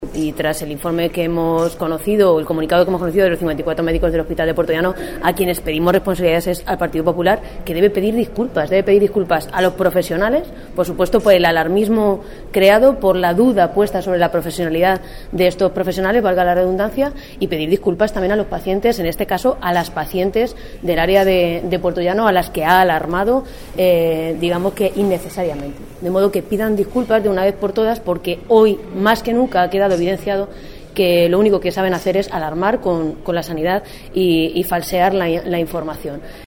La diputada del Grupo socialista, Ana Isabel Abengózar, ha señalado hoy que tras el comunicado que han firmado 54 médicos del hospital de Puertollano en el que apoyan la labor de sus compañeros especialistas que tienen el título sin homologar, a los dirigentes del PP “solo les queda un camino que es el de pedir disculpas a estos profesionales y a las pacientes atendidas por los mismos y dejar de una vez de atacar y denigrar la sanidad pública de nuestra comunidad autónoma”.
Cortes de audio de la rueda de prensa